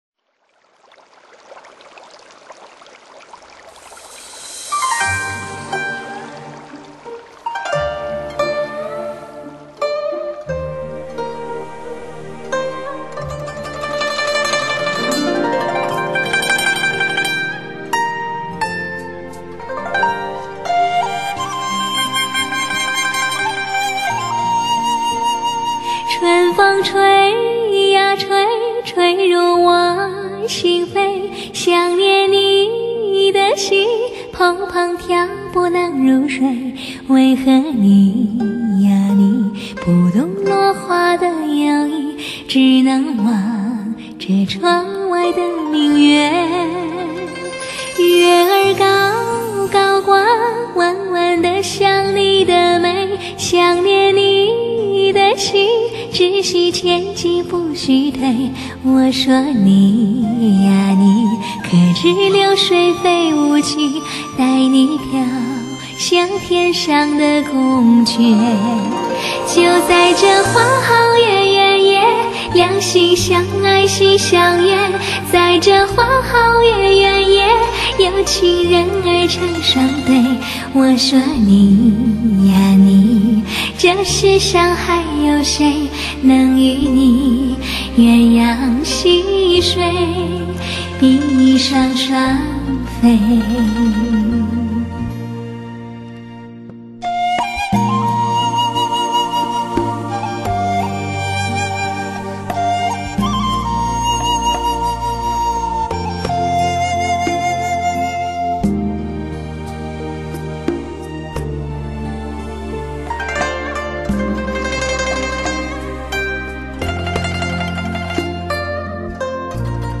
歌声的纯净，在这里悄然绽放。